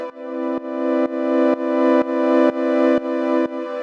GnS_Pad-dbx1:4_125-C.wav